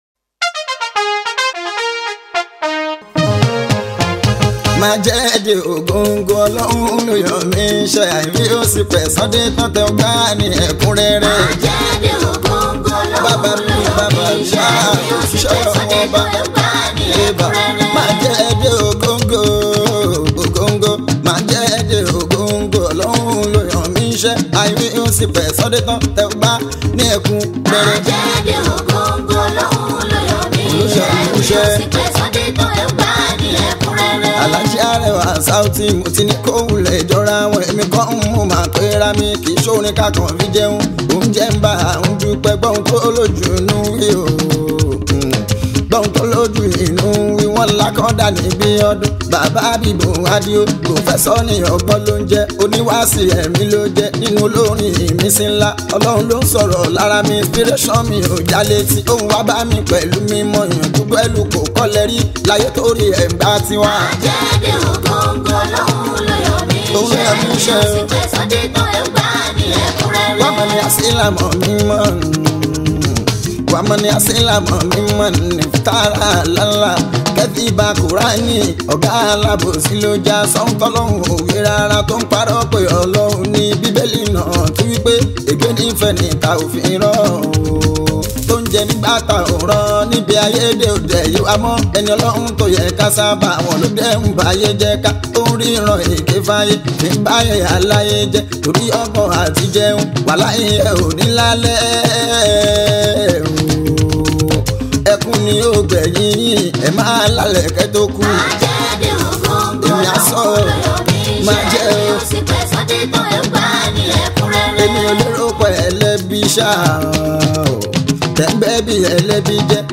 Surely another melodious Islamic songs for us today!